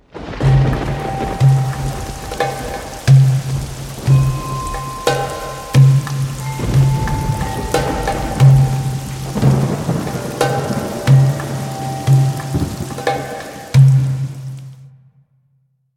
weather_alarm_thunderstorm.ogg